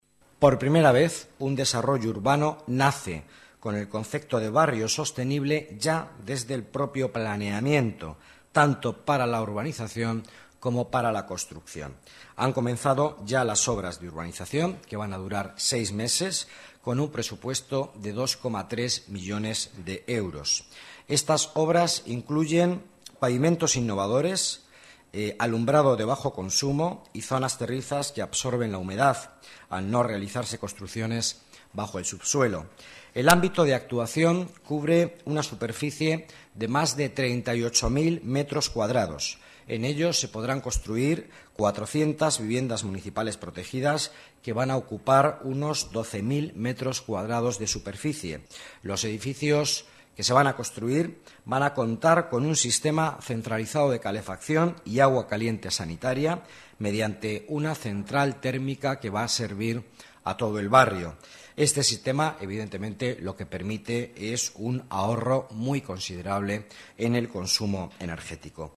Nueva ventana:Declaraciones del vicealcalde, Miguel Ángel Villanueva: Ecobarrio en Rosilla